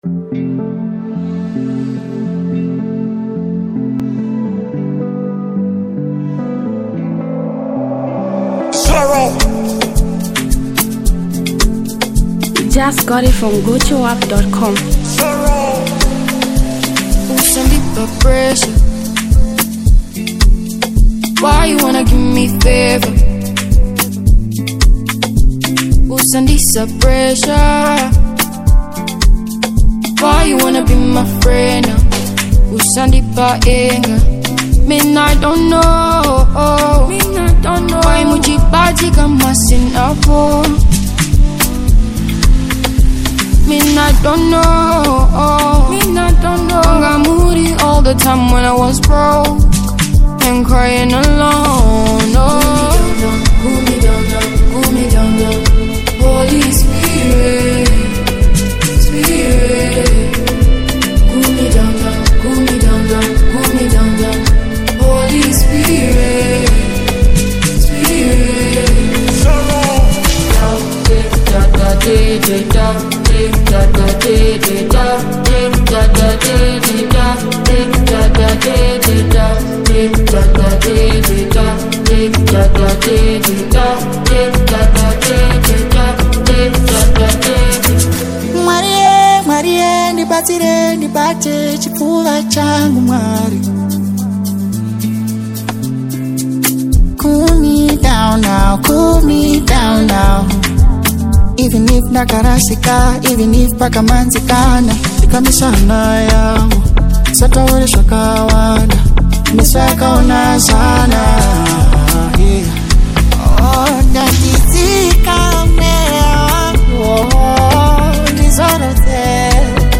blending Afrobeat, hip-hop
indigenous rhythms